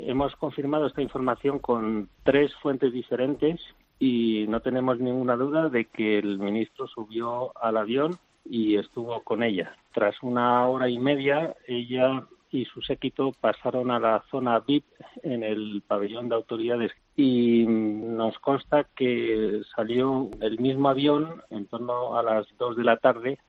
-Por 'La Linterna' de COPE, con Ángel Expósito, ha pasado el periodista de VozPópuli